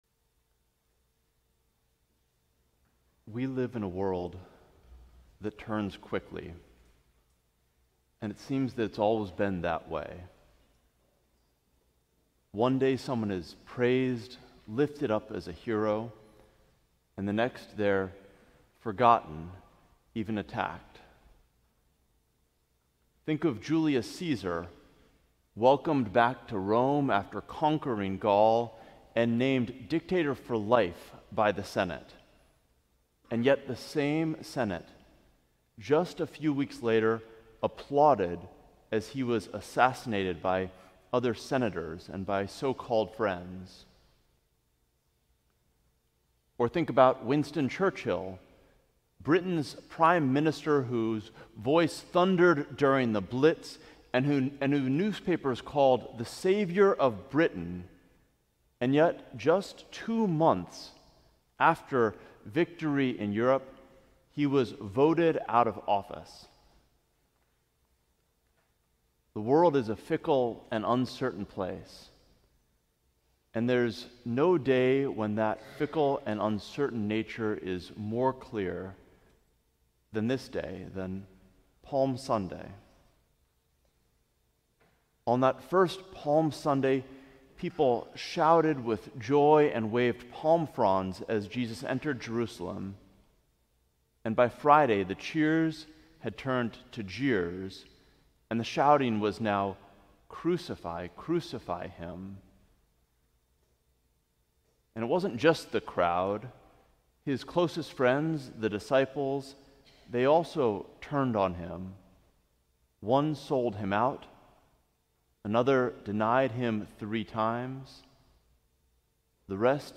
Sermon: Drawing Close